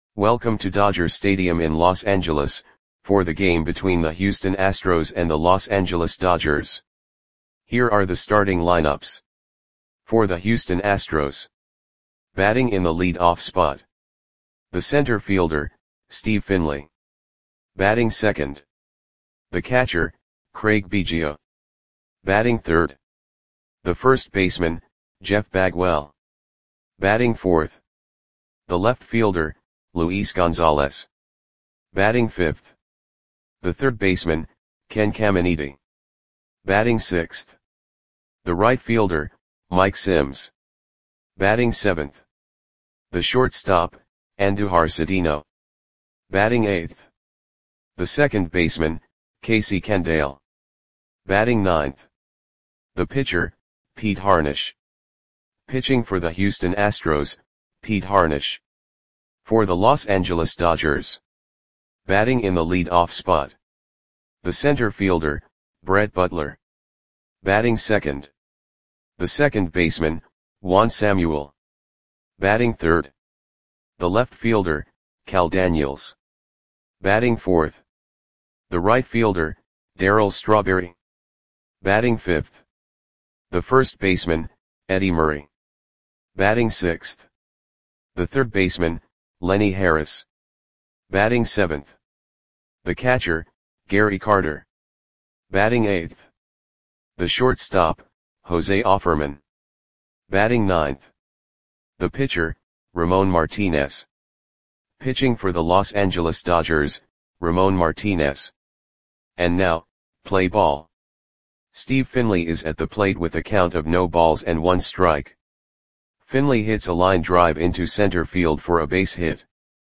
Lineups for the Los Angeles Dodgers versus Houston Astros baseball game on August 15, 1991 at Dodger Stadium (Los Angeles, CA).
Click the button below to listen to the audio play-by-play.